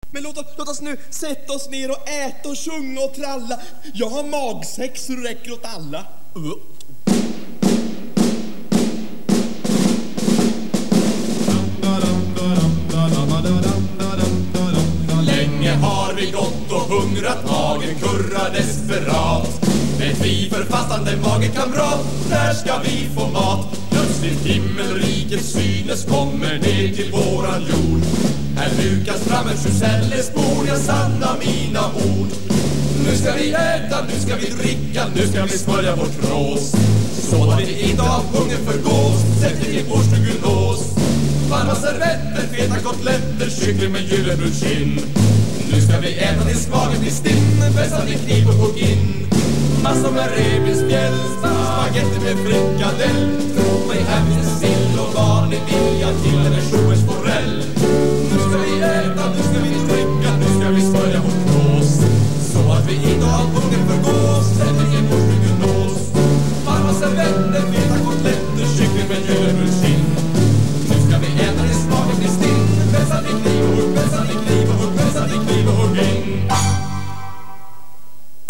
Linköpings Studentspex 10-års jubileum 1989
Denna visa sjungs på alla spexfester och annorstädes då spexet är samlat för att äta mat. wav